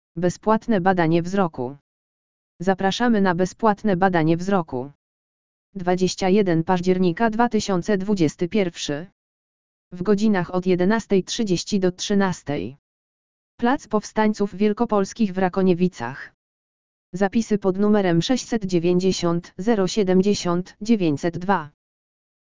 AUDIO LEKTOR BEZPŁATNE BADANIE WZROKU
audio_lektor_bezplatne_badanie_wzroku.mp3